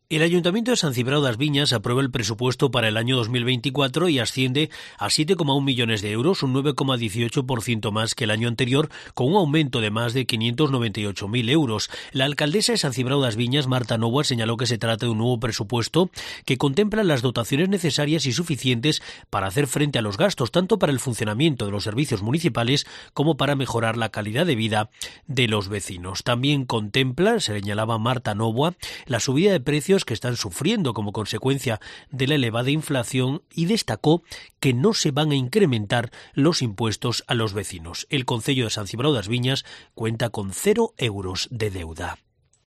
Pleno en el Concello de San Cibrao das Viñas